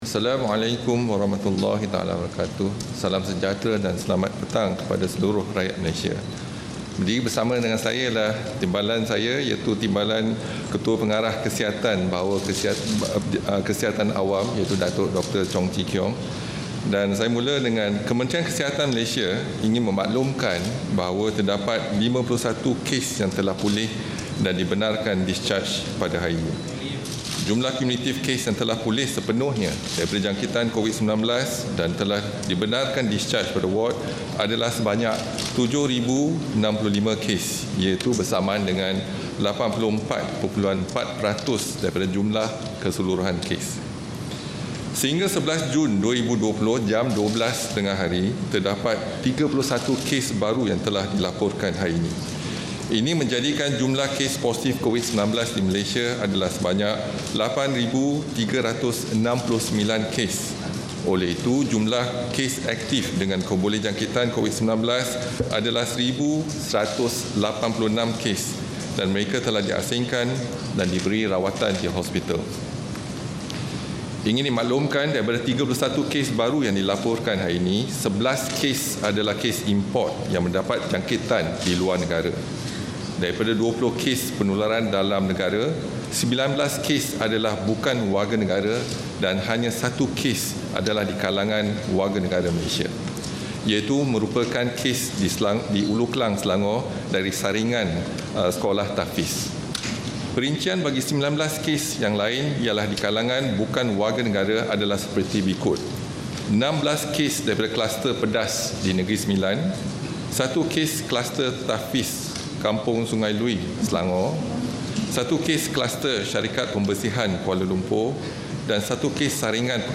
Ikuti sidang media harian oleh Ketua Pengarah Kementerian Kesihatan, Datuk Dr. Noor Hisham Abdullah berkaitan penularan wabak COVID-19.